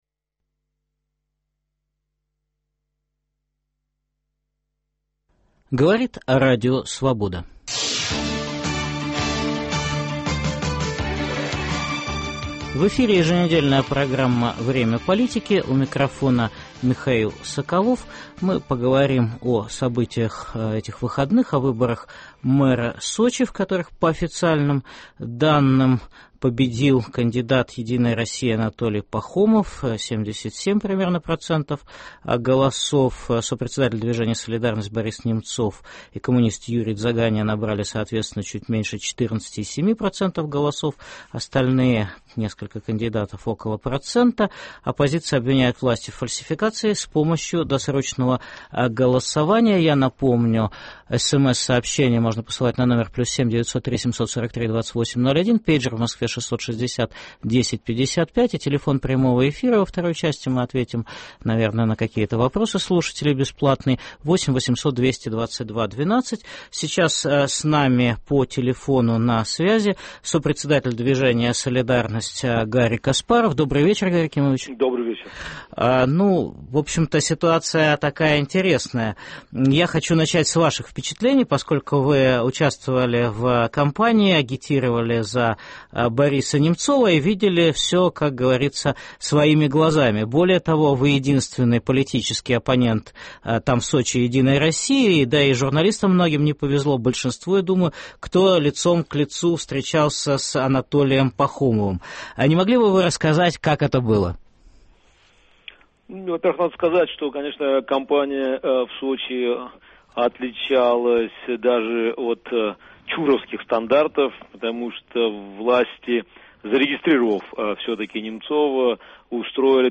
В прямом эфире итоги выборов мэра Сочи обсуждаем с сопредседателем движения «Солидарность» Гарри Каспаровым